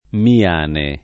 [ mi- # ne ]